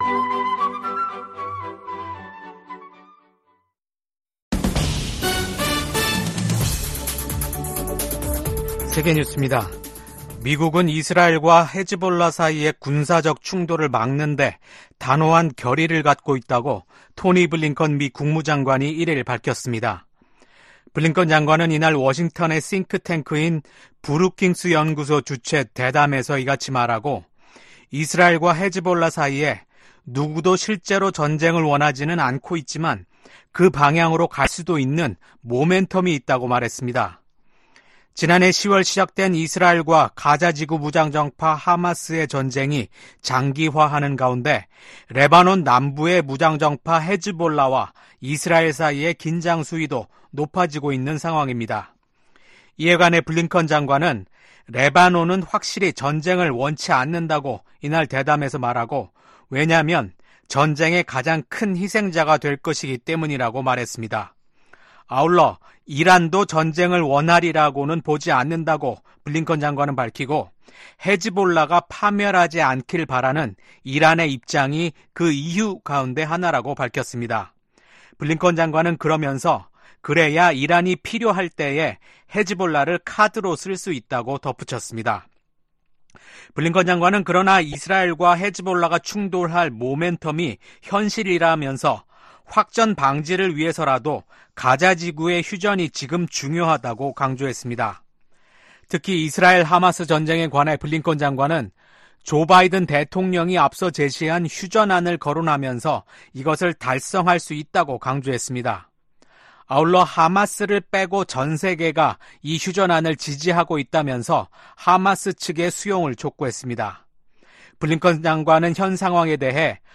VOA 한국어 아침 뉴스 프로그램 '워싱턴 뉴스 광장' 2024년 7월 3일 방송입니다. 지난해 10월 7일 하마스의 이스라엘 공격 당시 피해를 입은 미국인들이 북한 등을 상대로 최소 40억 달러에 달하는 손해배상 소송을 제기했습니다. 북한은 어제(1일) 초대형 탄두를 장착하는 신형 전술탄도미사일 시험발사에 성공했다고 밝혔습니다.